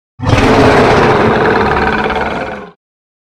Play, download and share Drachen Schrei original sound button!!!!
drachen-schrei.mp3